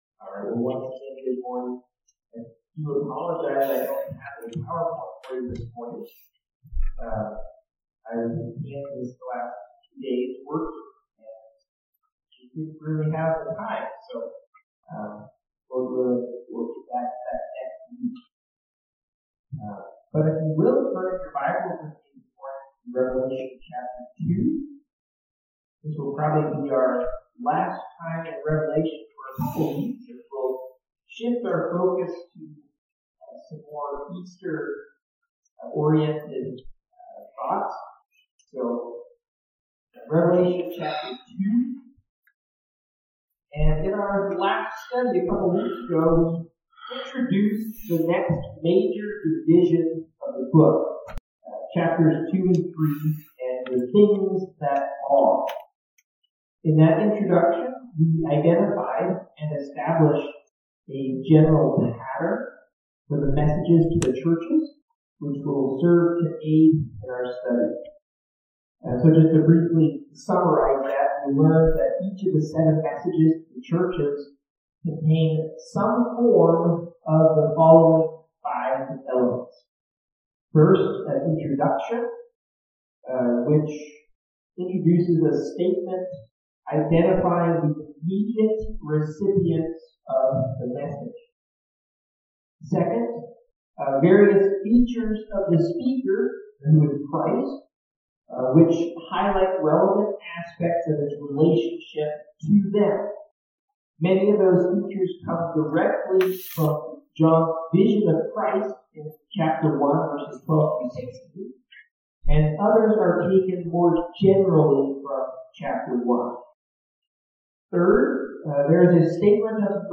Audio above has a problem with the first 3 mins of Audio.